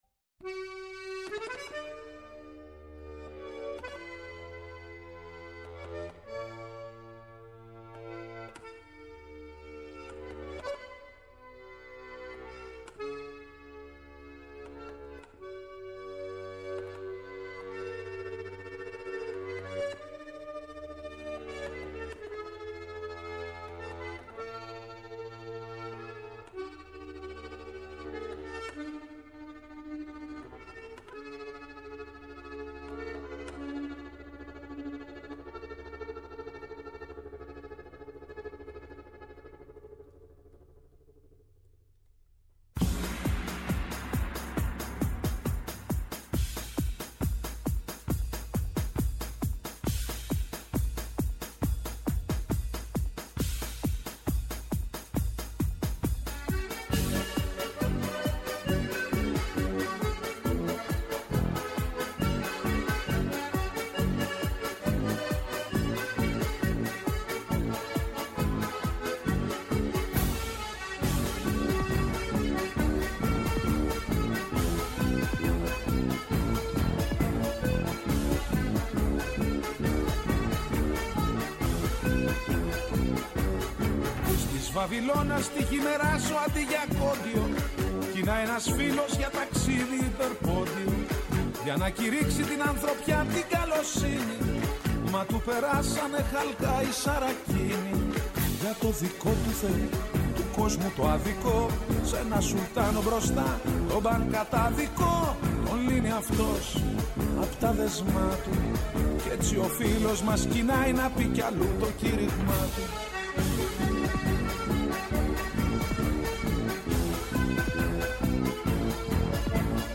Καλεσμένη στο στούντιο